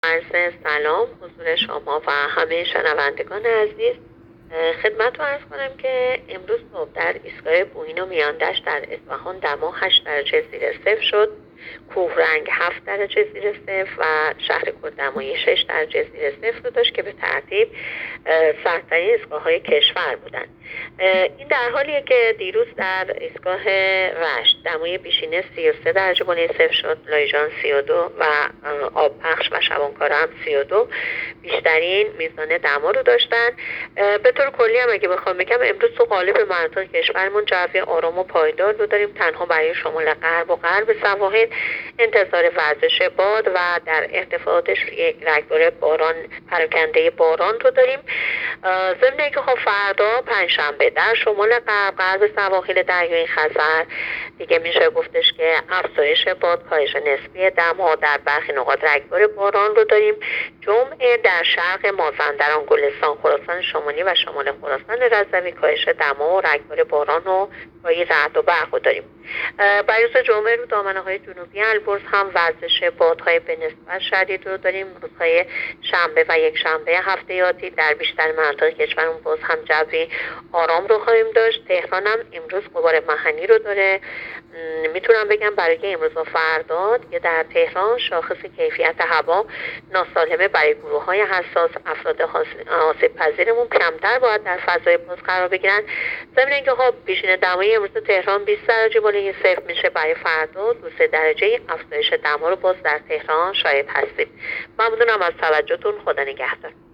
گزارش رادیو اینترنتی از آخرین وضعیت آب و هوای ۲۹ بهمن؛